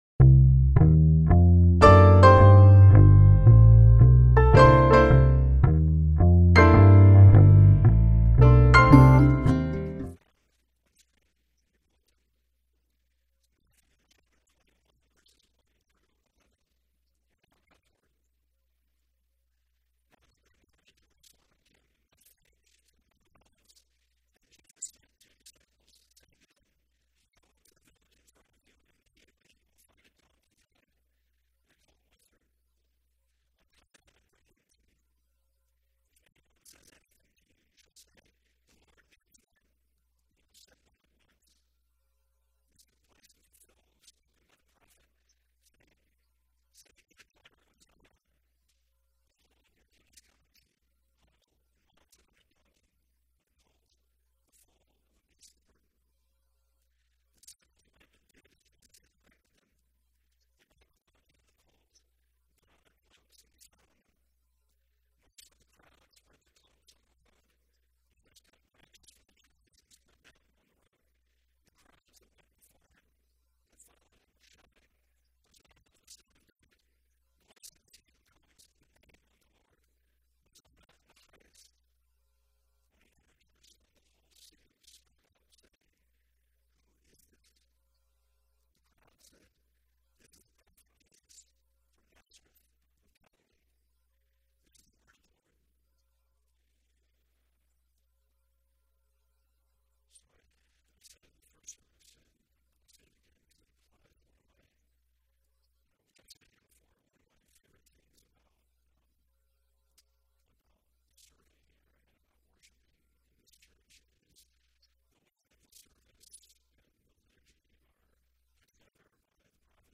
Holy Week Passage: Matthew 21:1-11 Service Type: Sunday Worship « From Beginning To Babel